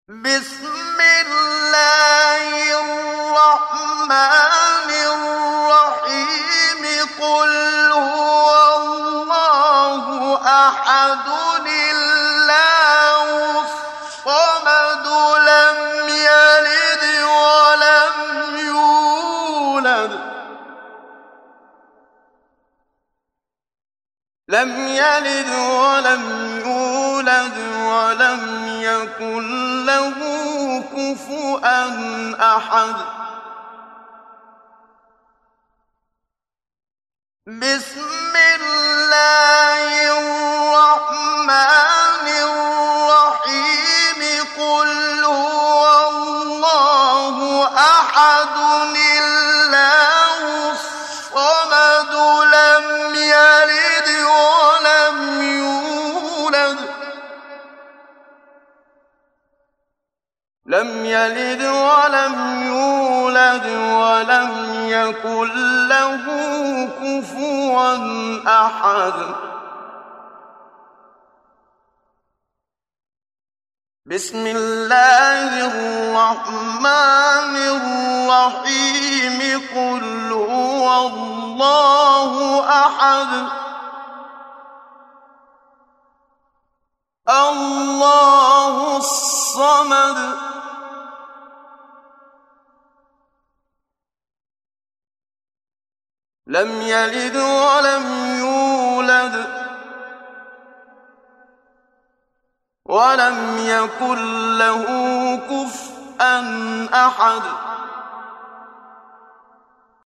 محمد صديق المنشاوي – تجويد – الصفحة 9 – دعاة خير